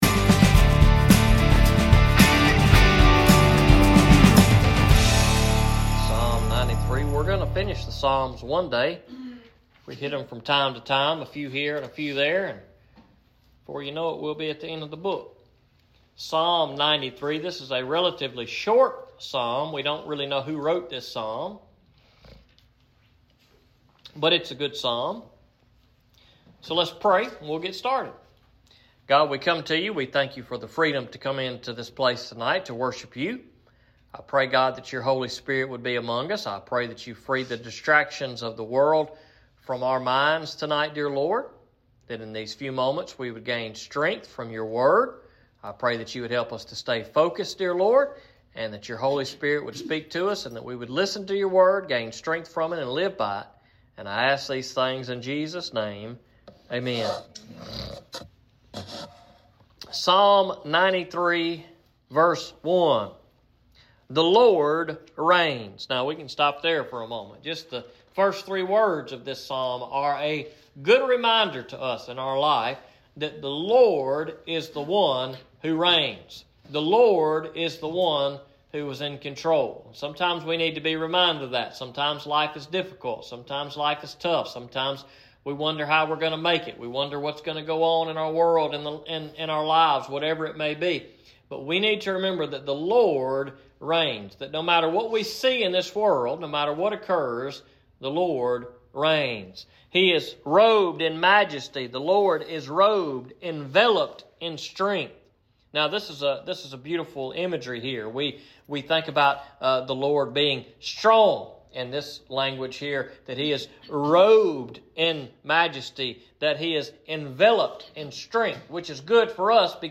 Sermons preached at Enterprise Baptist Church in Liberty MS